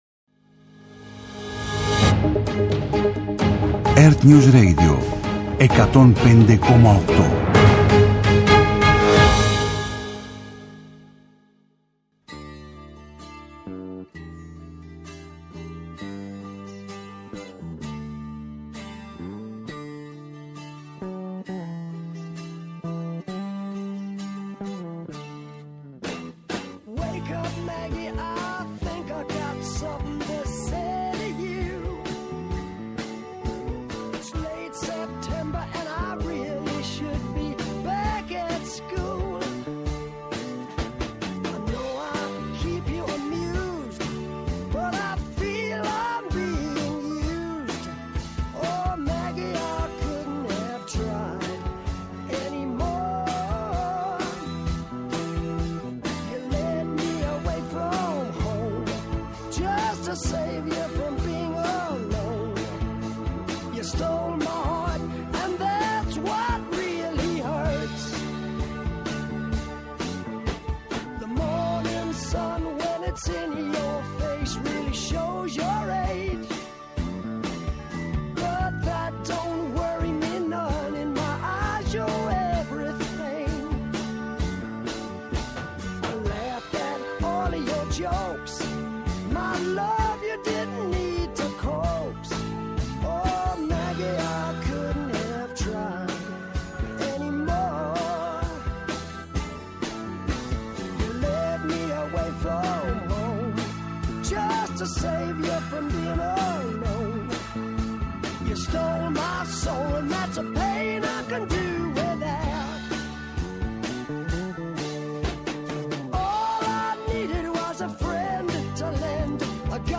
-Απόσπασμα από την ενημέρωση των πολιτικών συντακτών, από τον Κυβερνητικό Εκπρόσωπο, Παύλο Μαρινάκη